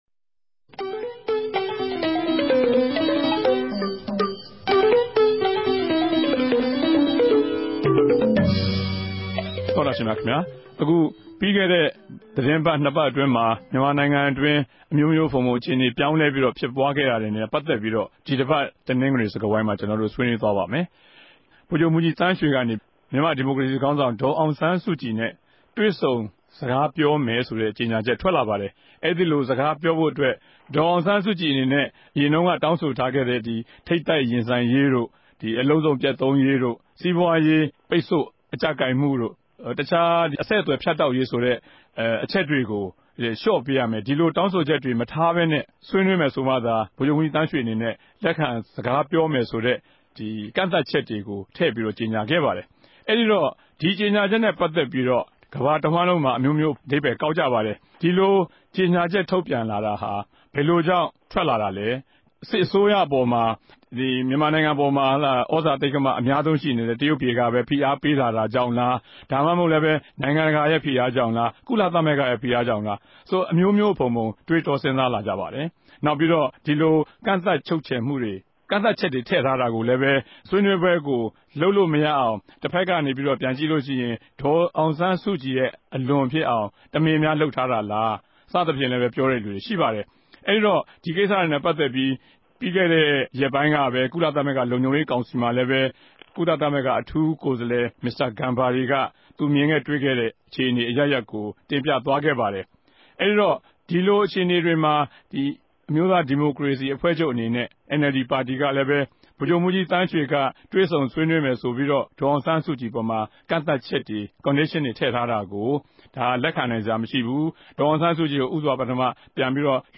တနဂဿေိံြ ဆြေးေိံြးပြဲစကားဝိုင်း
တနဂဿေိံစြကားဝိုင်း